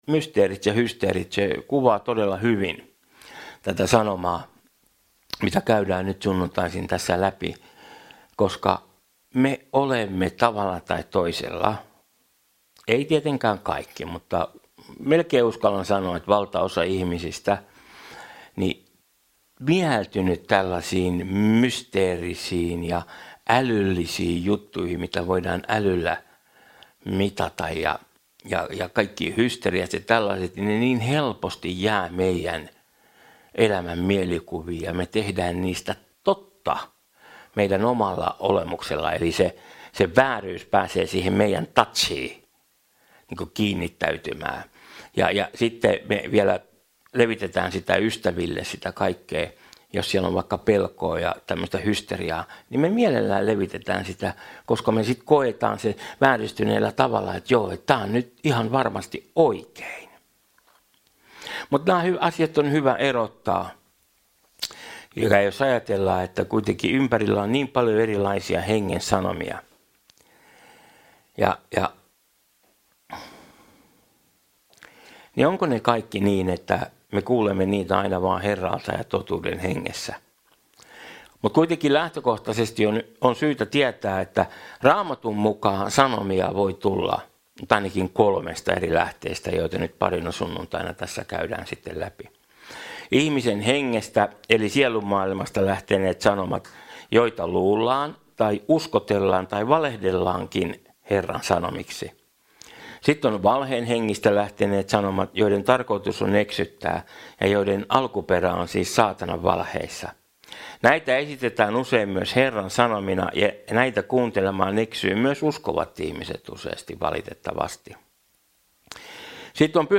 Service Type: Raamattutunti